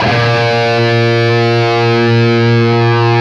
LEAD A#1 CUT.wav